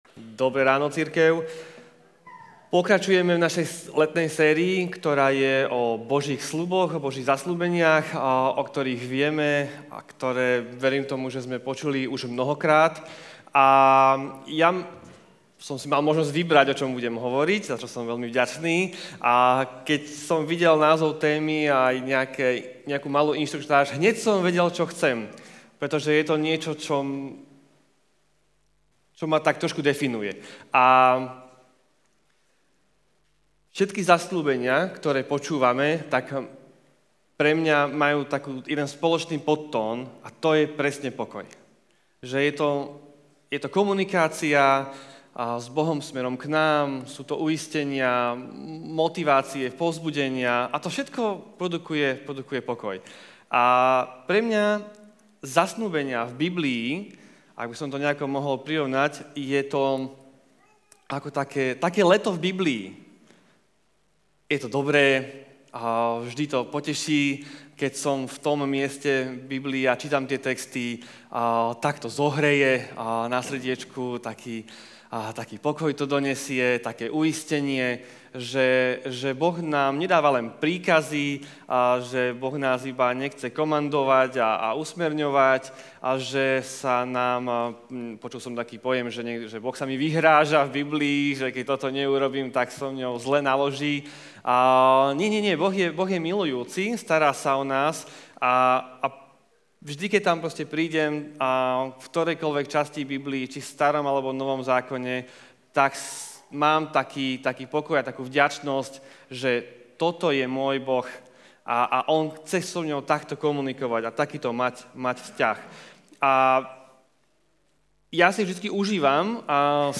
Zaručene v pohode Kázeň týždňa Zo série kázní